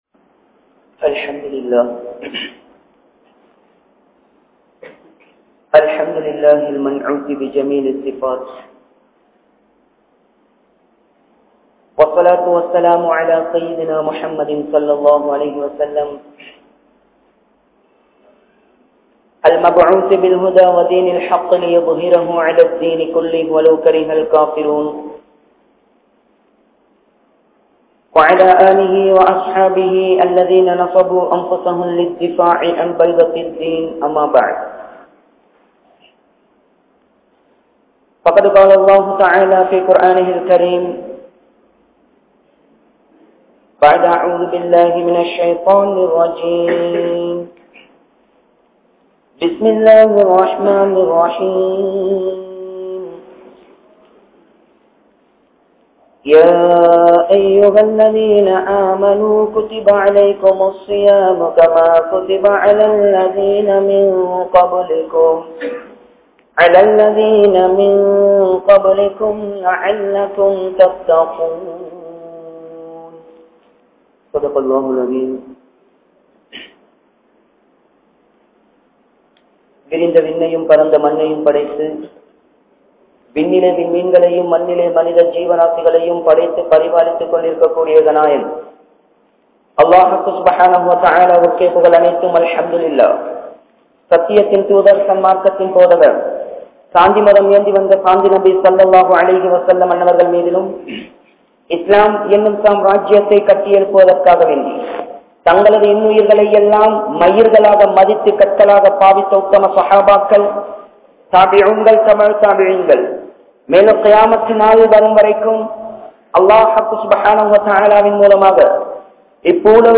Naraham | Audio Bayans | All Ceylon Muslim Youth Community | Addalaichenai